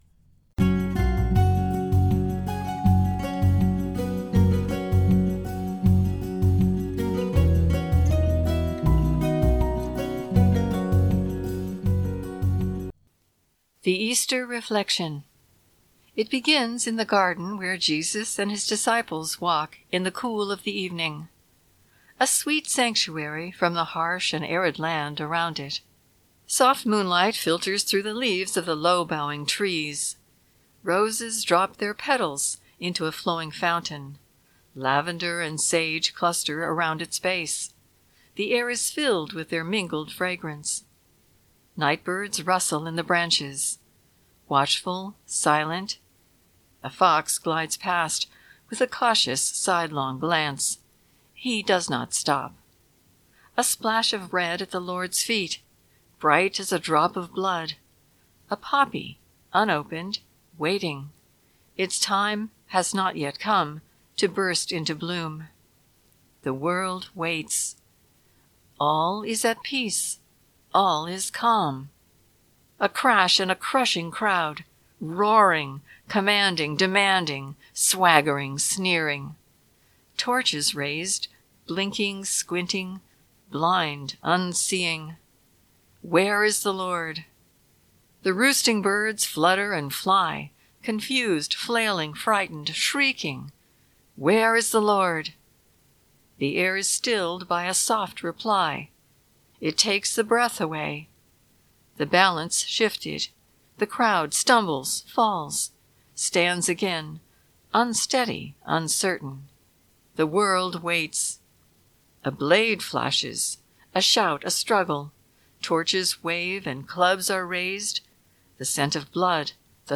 The Spoken Version